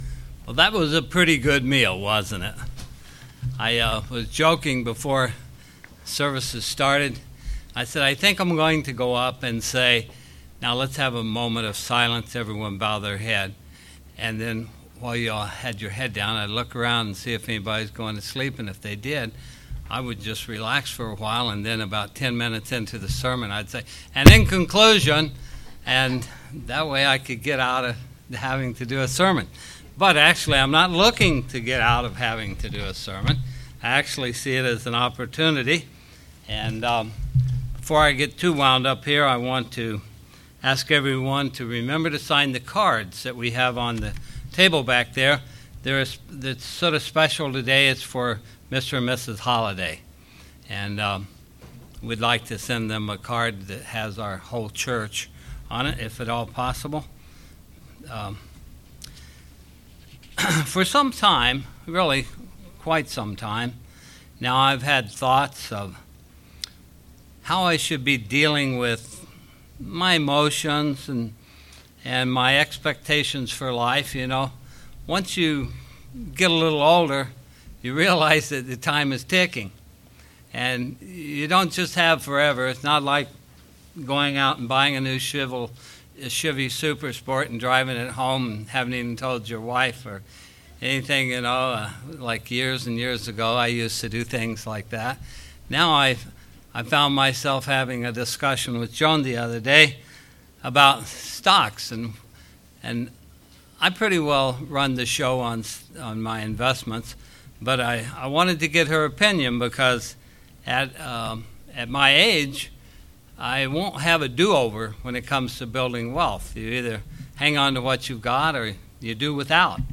A sermon looking through the life of Moses and the lessons we can learn with dealing with disappointment.